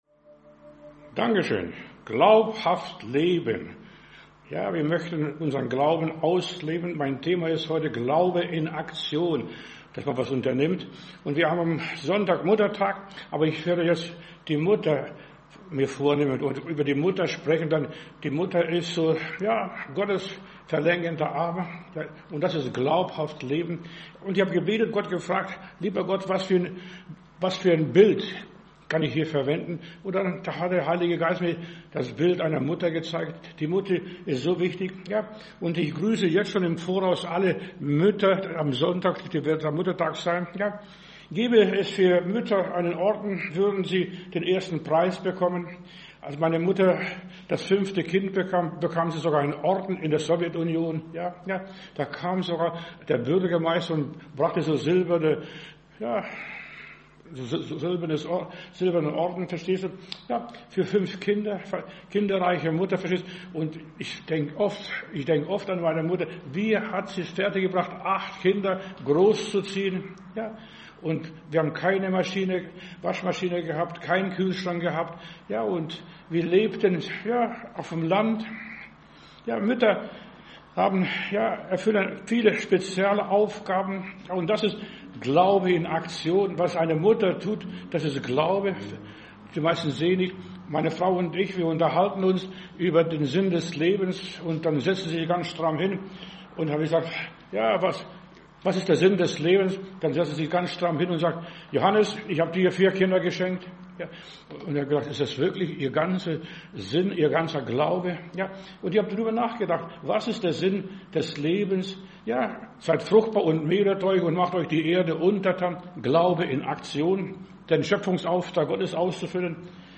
Predigt herunterladen: Audio 2025-05-09 Glaube in Aktion Video Glaube in Aktion